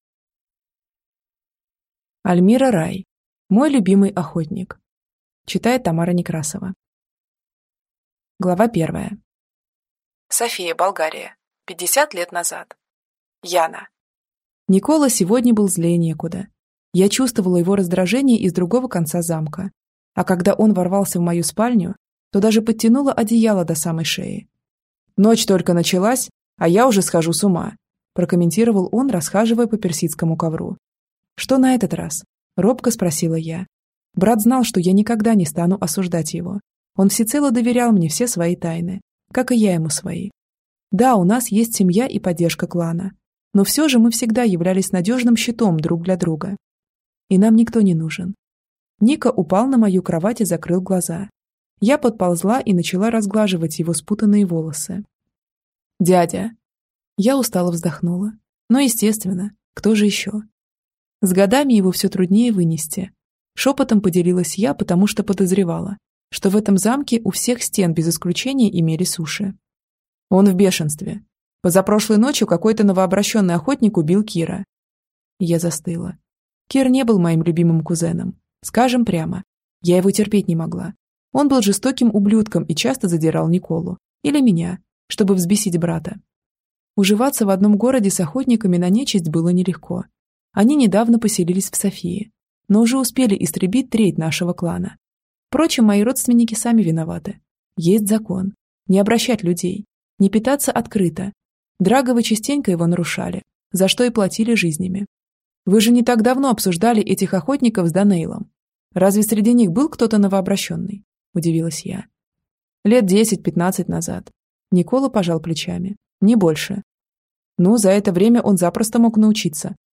Аудиокнига Мой любимый охотник | Библиотека аудиокниг